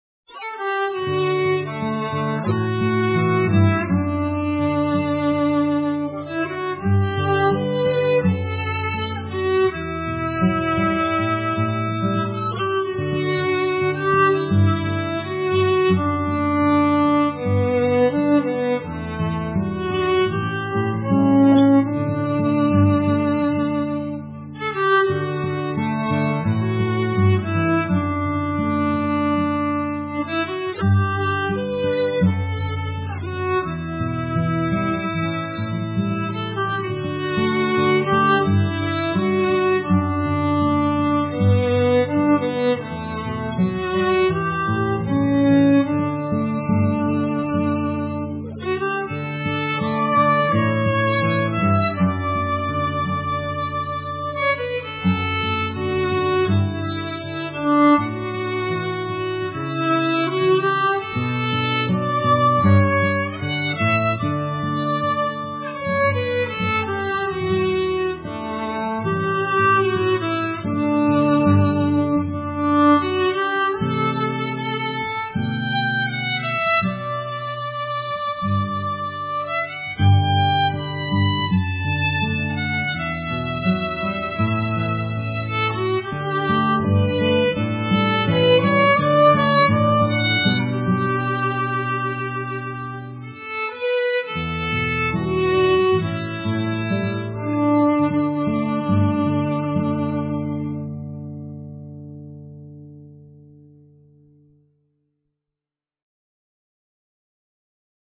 on guitar and read narrative